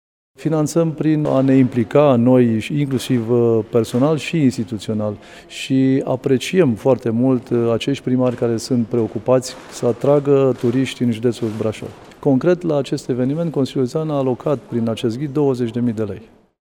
Evenimentul cultural din Cetatea Feldioara este sprijinit financiar de administrația județeană cu suma de 20.000 de lei. Vicepreședintele Consiliului Județean Brașov, Șerban Todorică: